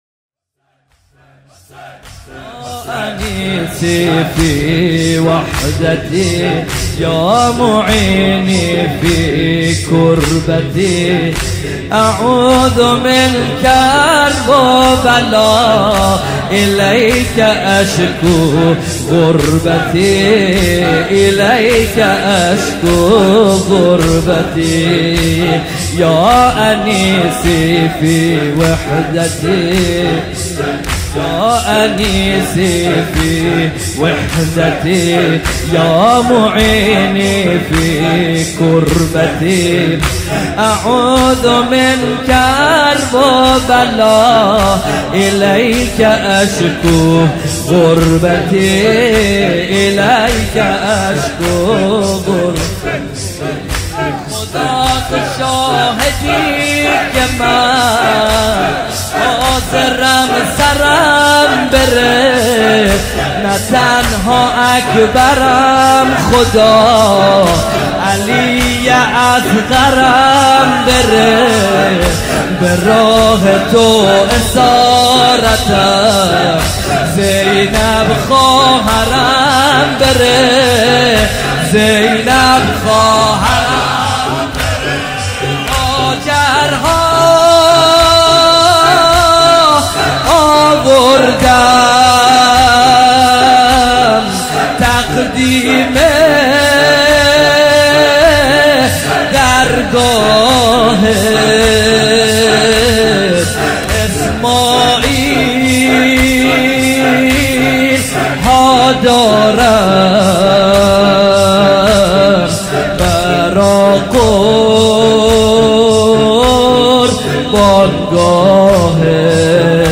واحد (فارسی،عربی) | خدا تو شاهدی که من، حاضرم سرم بره
شب 2 محرم- سال 1439 هجری قمری | هیأت علی اکبر بحرین